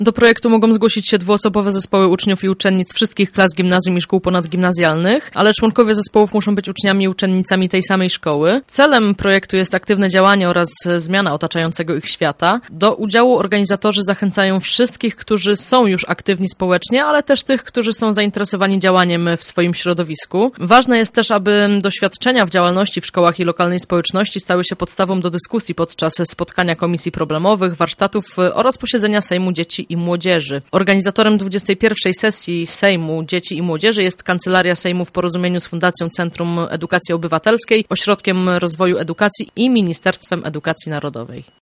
Więcej szczegółów zna nasza reporterka